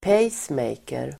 Ladda ner uttalet
pacemaker substantiv (engelska), pacemaker [English]Uttal: [p'ej:smejker] Böjningar: pacemakern, pacemakrarSynonymer: hjärtstimulatorDefinition: elektrisk apparat som stimulerar hjärtverksamheten